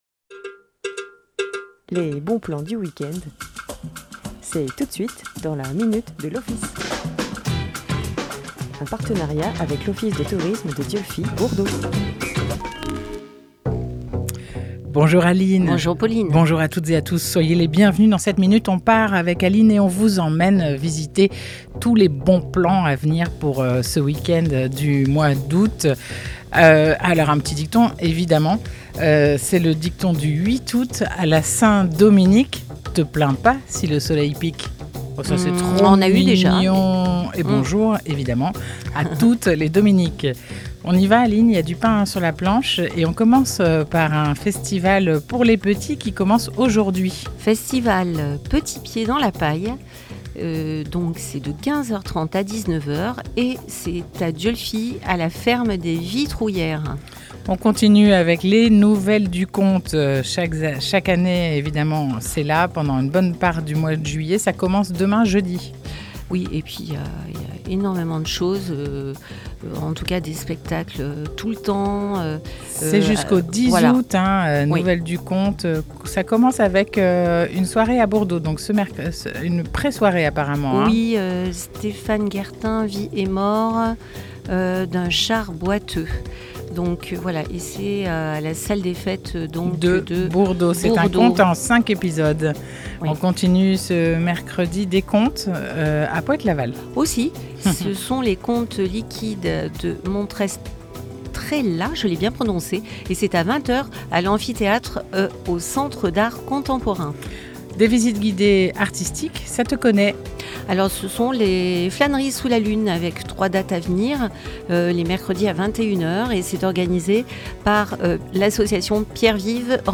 Chaque mercredi à 9h30 en direct